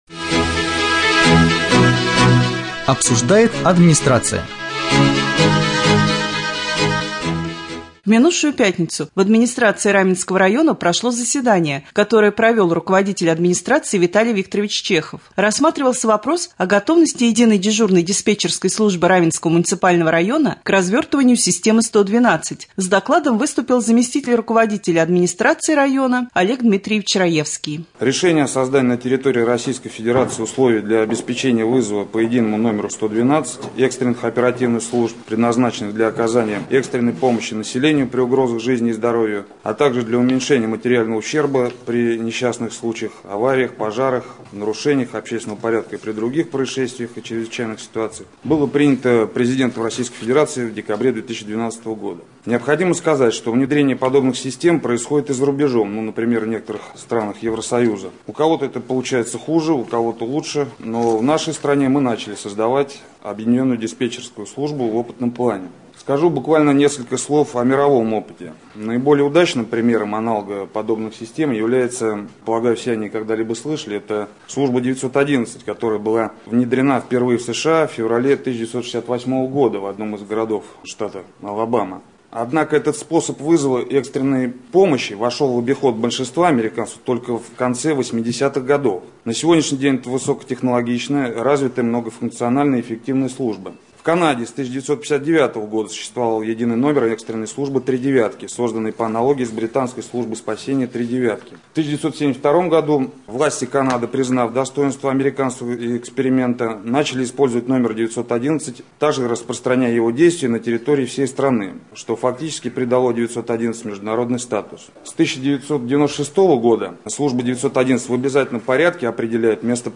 18.11.2014г. в эфире Раменского радио - РамМедиа - Раменский муниципальный округ - Раменское